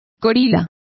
Complete with pronunciation of the translation of bouncers.